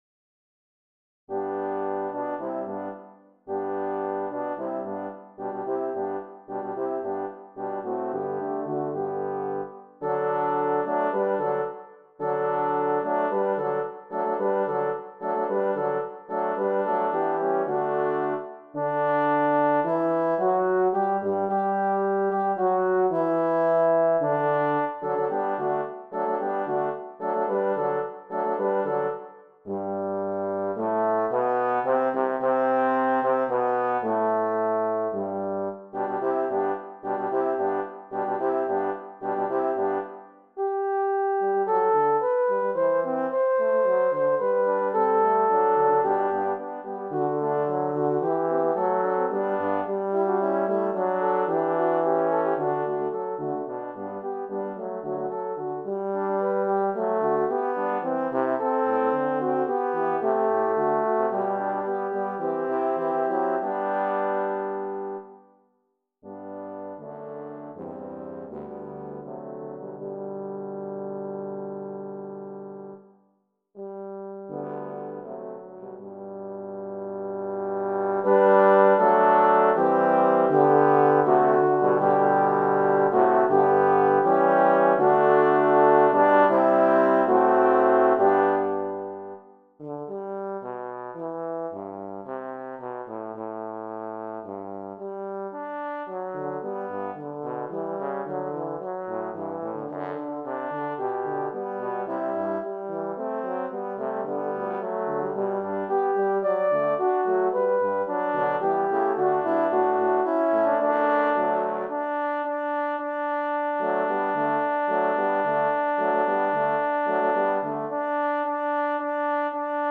HALLELUJAH CHORUS by Handel for Horn Quartet
CLASSICAL MUSIC; CHRISTIAN MUSIC